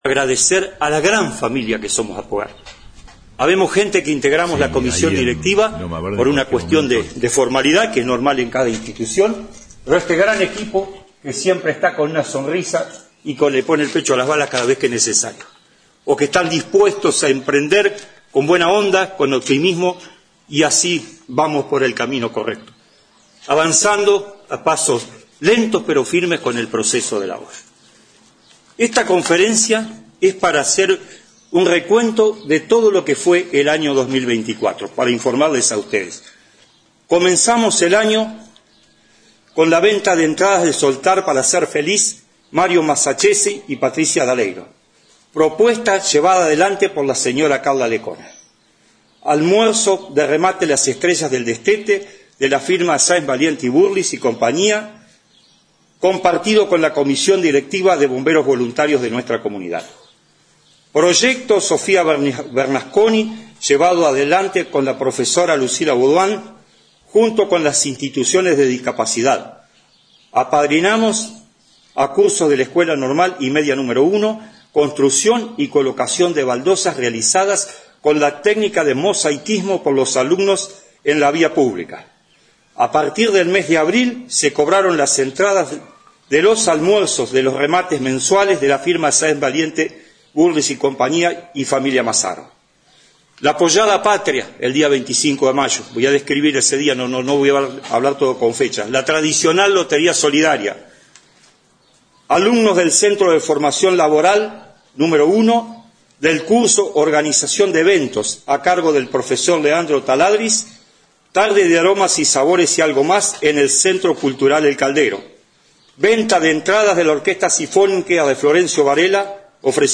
conferencia-aphogard.mp3